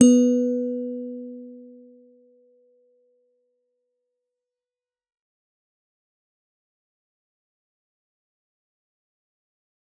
G_Musicbox-B3-f.wav